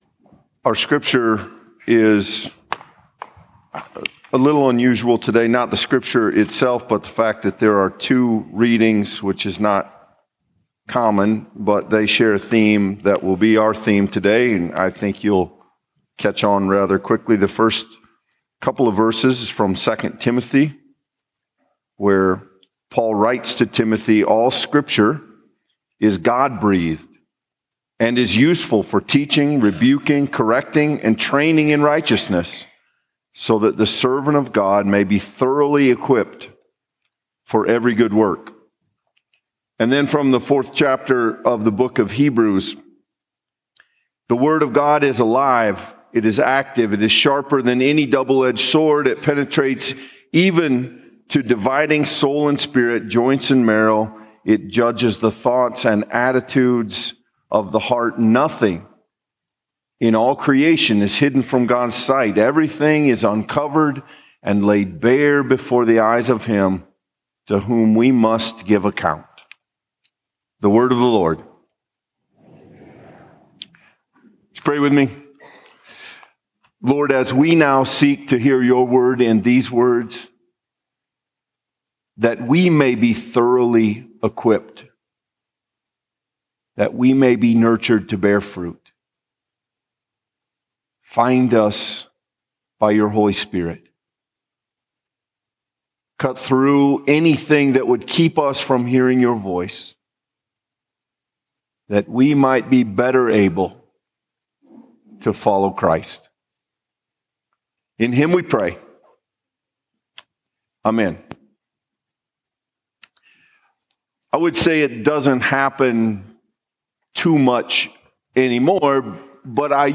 The 8:50 worship service at First Presbyterian Church in Spirit Lake.